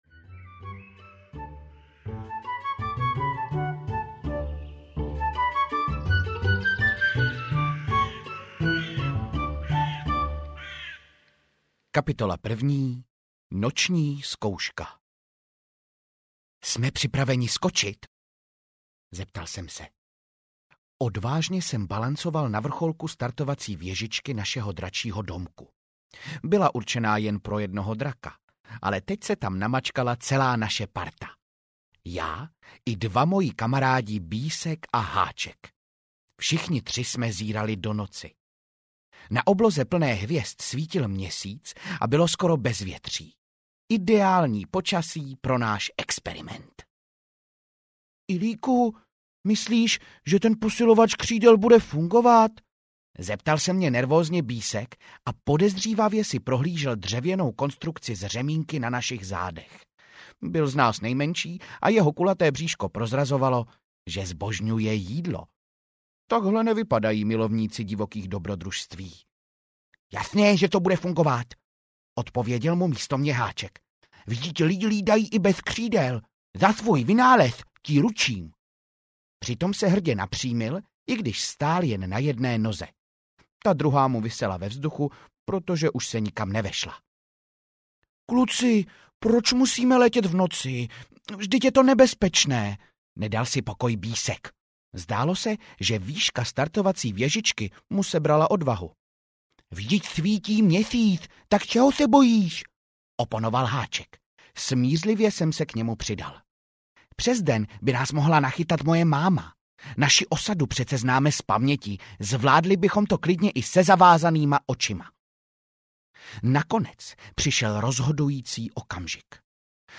Kroniky beskydských draků audiokniha
Ukázka z knihy